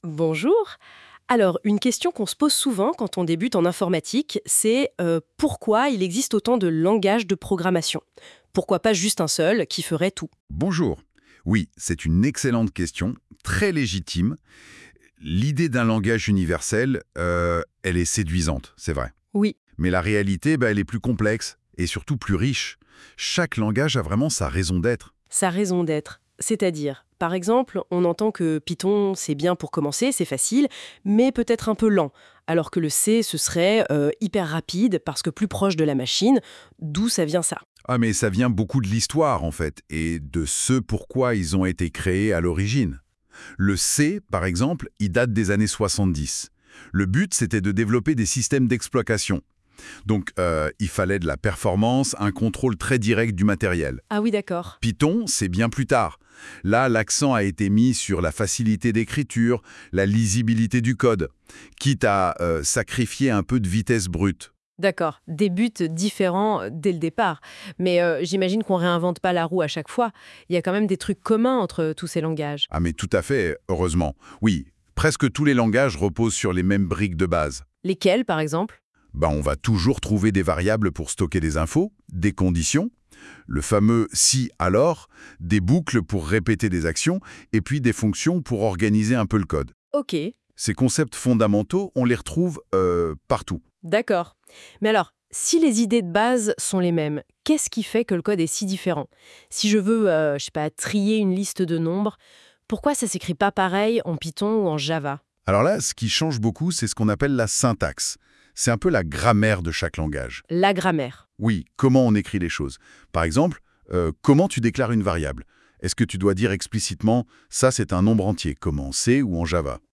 icone IA Résumé audio
Ce résumé audio a été généré par une intelligence artificielle (Notebooklm de Google) à partir uniquement du contenu de cette page.